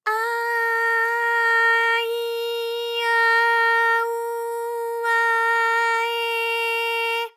ALYS-DB-001-JPN - First Japanese UTAU vocal library of ALYS.
a_a_i_a_u_a_e.wav